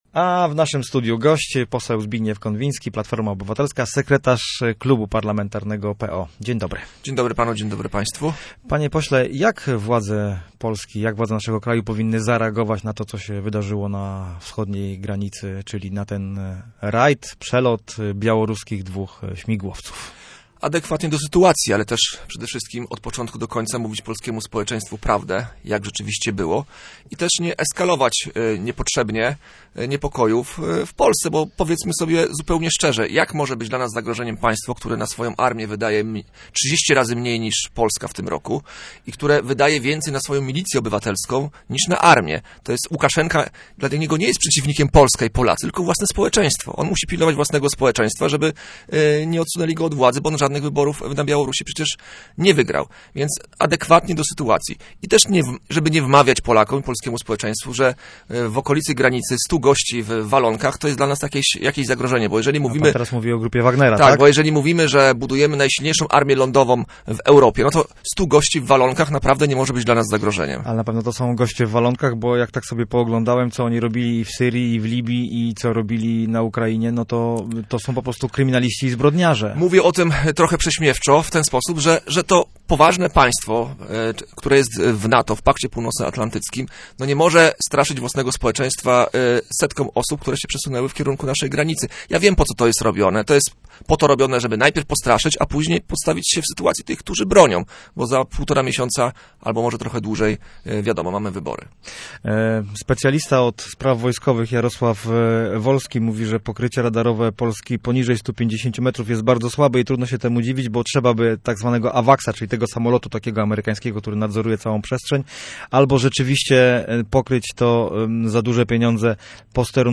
Zbigniew Konwiński był gościem miejskiego programu Radia Gdańsk Studio Słupsk 102 FM.
Konwinski_gosc_OK.mp3